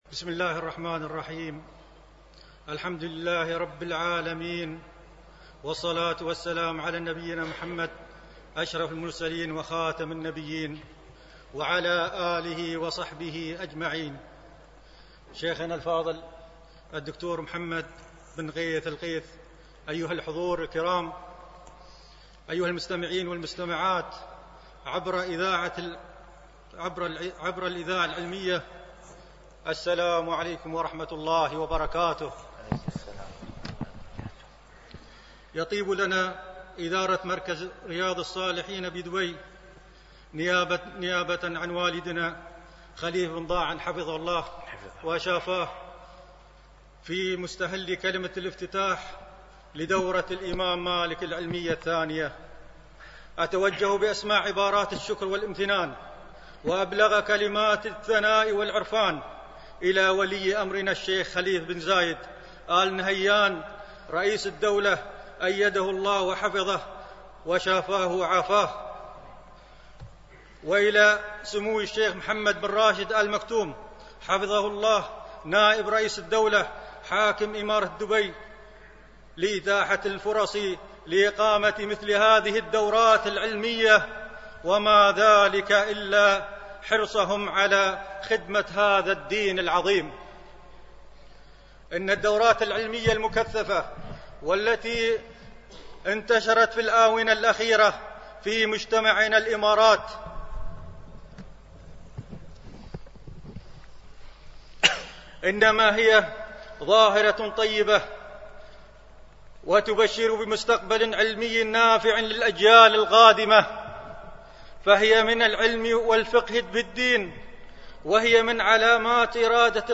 المحاضرة الافتتاحية: درر من حياة الإمام مالك